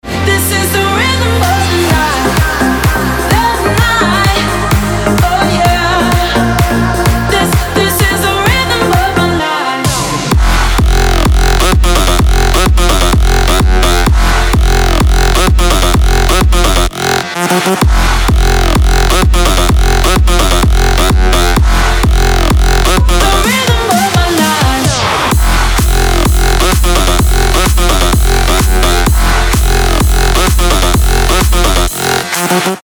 • Качество: 320, Stereo
electro house
Жесткий современный ремикс ретро-хита 90х